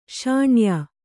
♪ śaṇyā